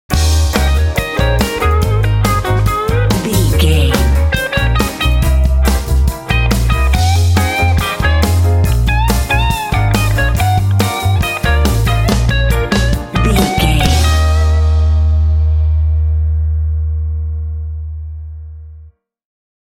Aeolian/Minor
E♭
funky
groovy
bright
piano
drums
electric guitar
bass guitar
blues
jazz